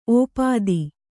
♪ ōpādi